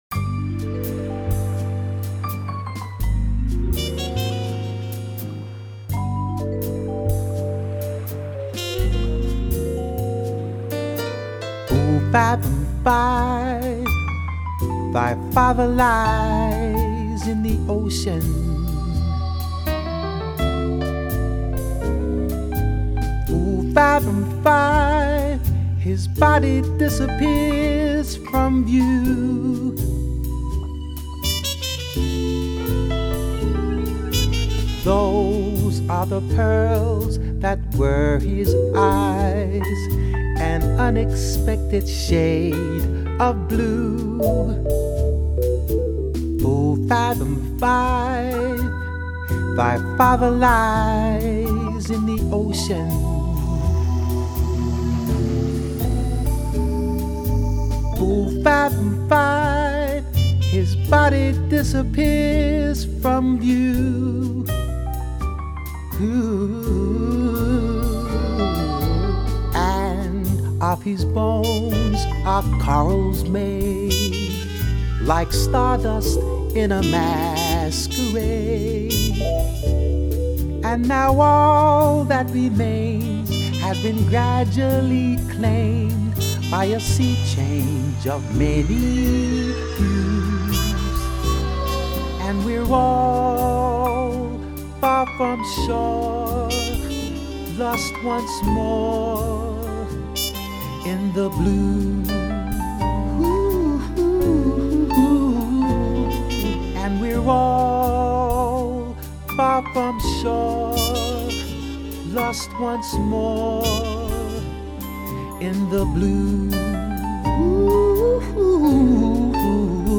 full vocal